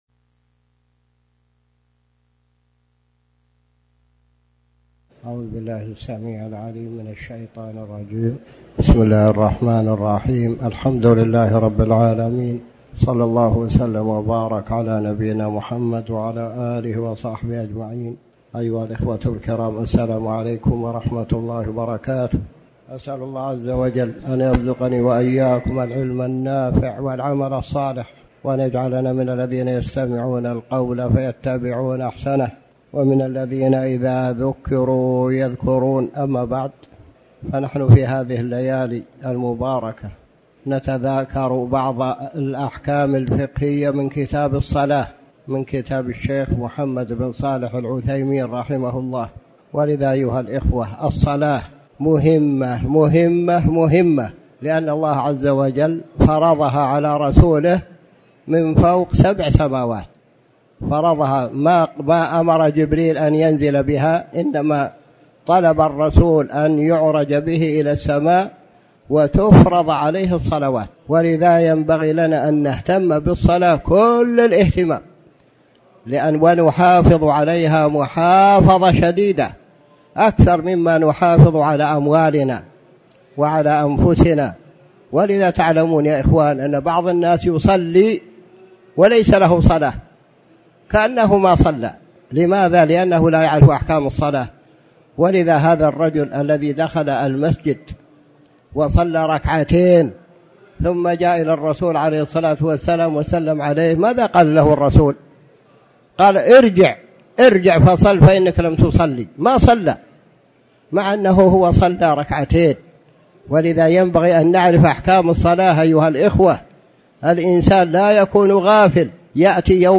تاريخ النشر ١٢ صفر ١٤٤٠ هـ المكان: المسجد الحرام الشيخ